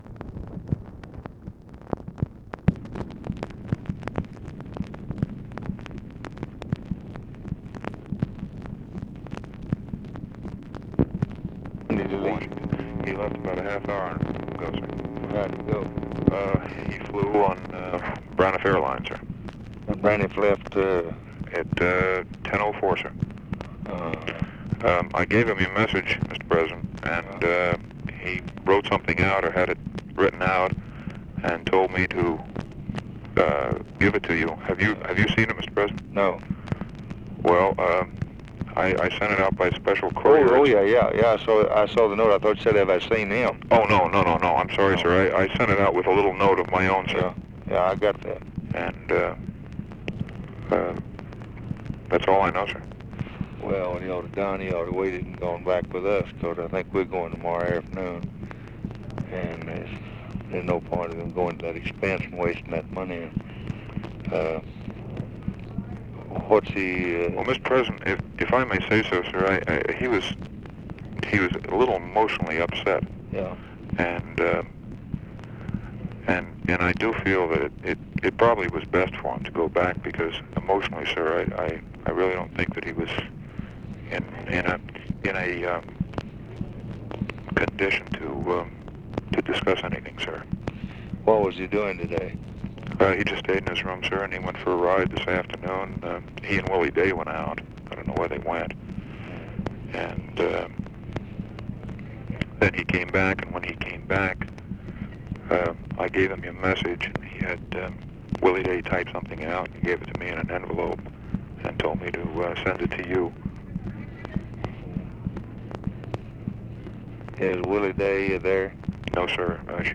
Conversation with MAC KILDUFF, August 31, 1964
Secret White House Tapes